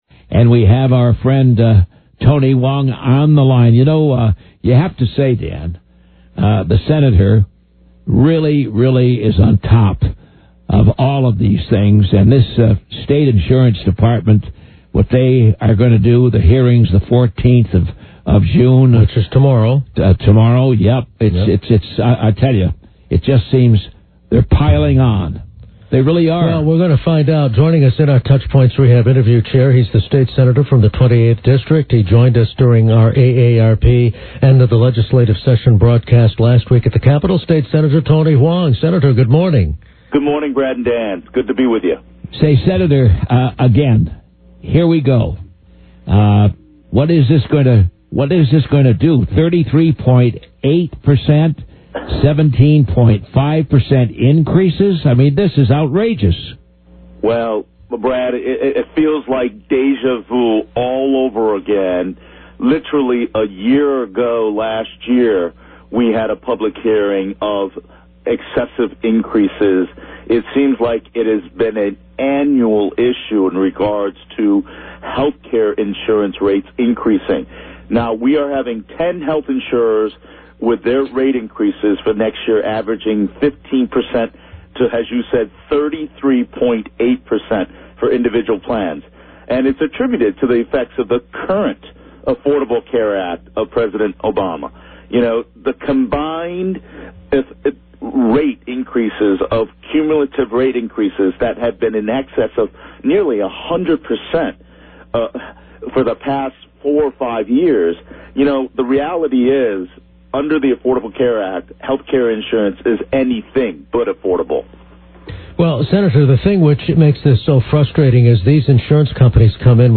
Connecticut state Sen. Tony Hwang, R-28, is concerned about proposed double-digit rate hikes by health insurers Anthem Health Plans and ConnectiCare Benefits Inc. The state Insurance Department is holding a public hearing on the proposed hike and in this interview, Sen. Hwang says he's looking for public input.